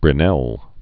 (brĭ-nĕl)